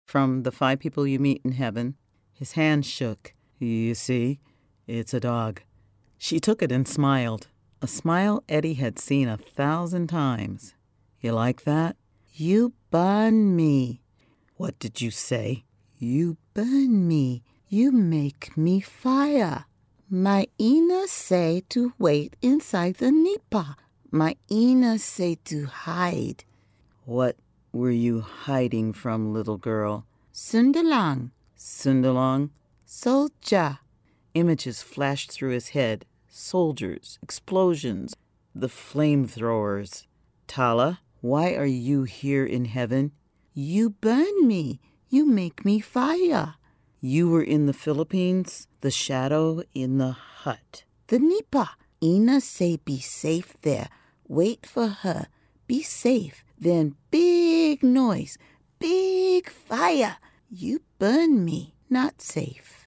Narrator voice - intelligent, warm, conversational; broad emotional range; large repertoire of characters
Sprechprobe: eLearning (Muttersprache):